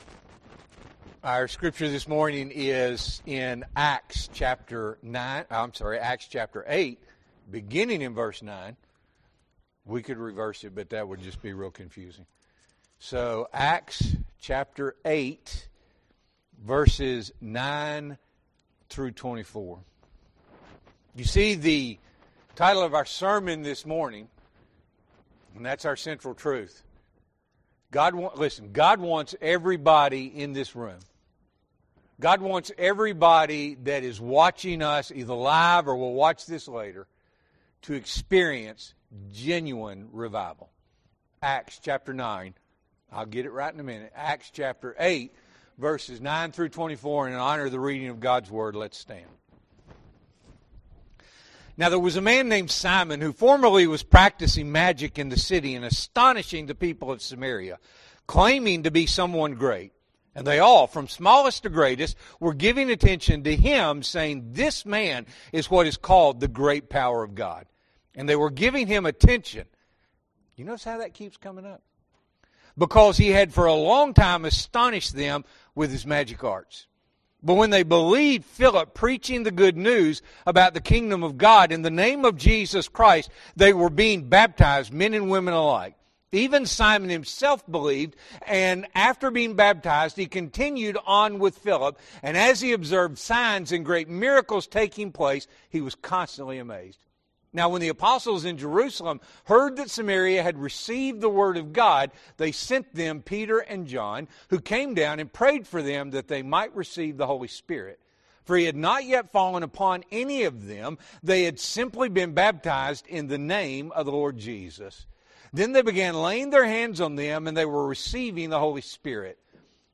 March 15, 2026 – Morning Worship